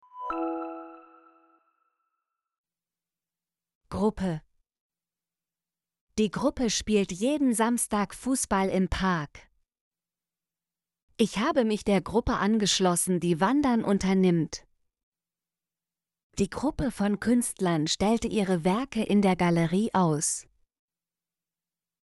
gruppe - Example Sentences & Pronunciation, German Frequency List